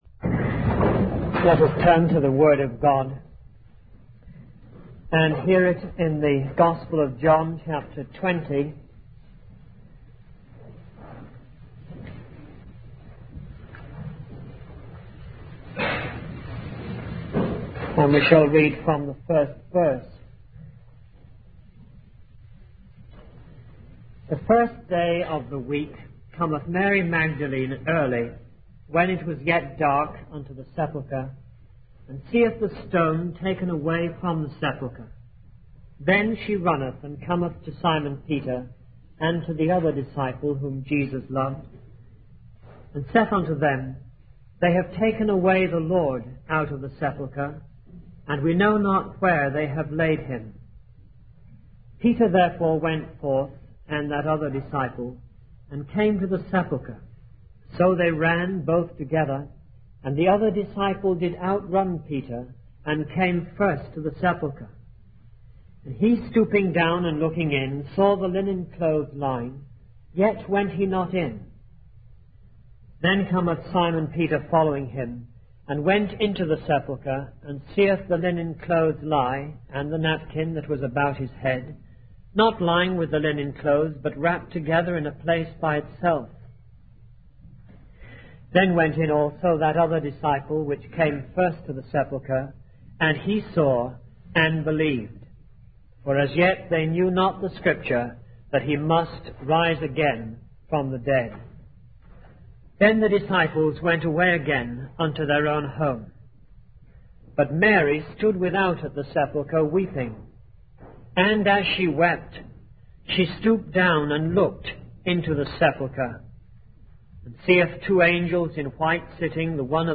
In this sermon, the preacher emphasizes the importance of valuing and utilizing our relationship with God. He mentions the story of Jacob serving seven years for the love of Rachel, highlighting the devotion and love that should be directed towards Christ. The preacher also discusses the danger of forgetting our first love for God and encourages the congregation to maintain a deep love and devotion to Him.